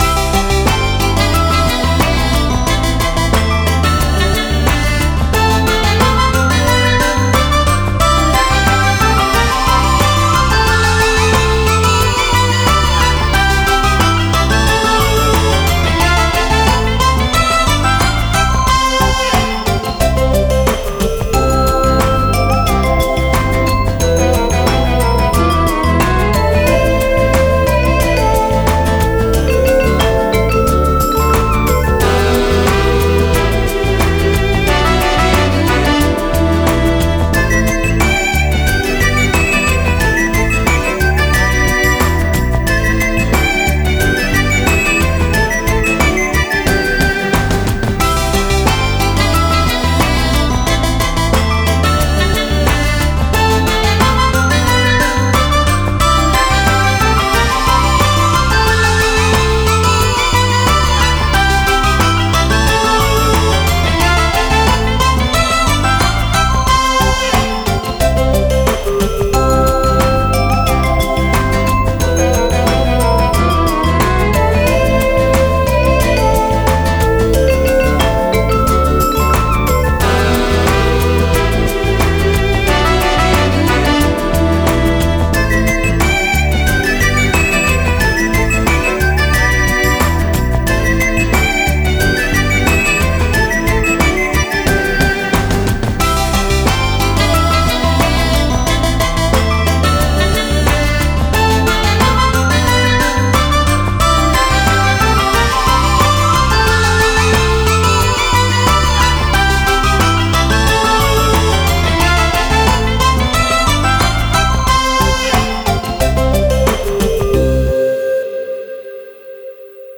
• Категория: Детские песни
Слушать минус